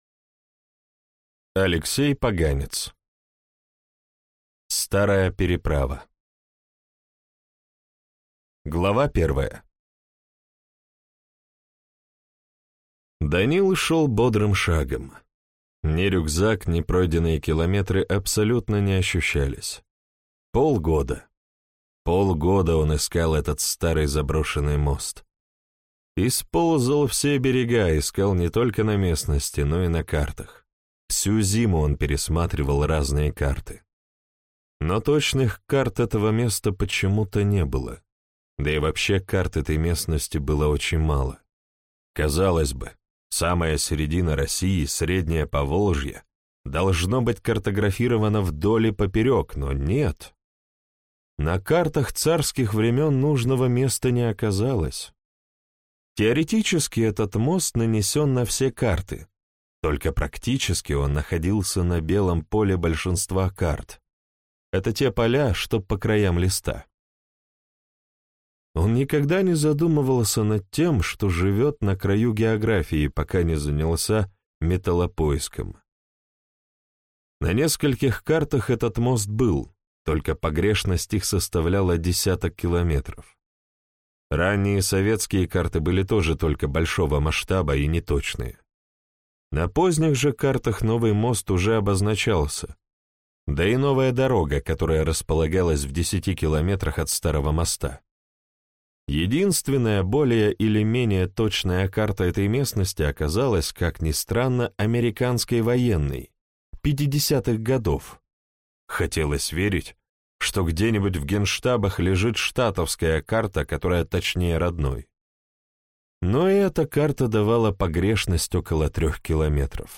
Бандитский Петербург. Часть первая. Изнанка столицы империи (слушать аудиокнигу бесплатно) - автор Андрей Константинов